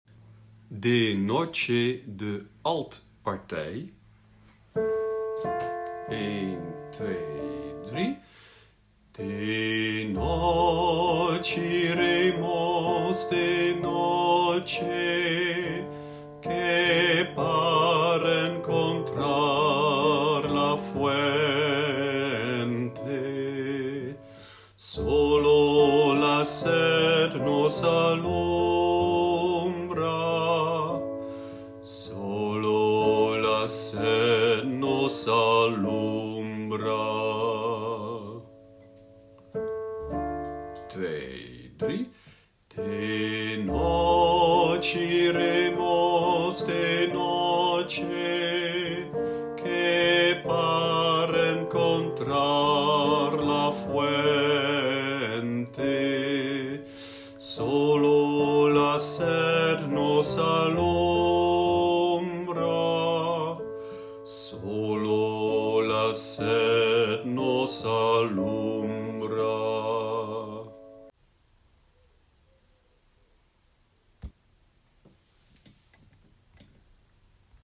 alt
De-noche-iremos-alt.mp3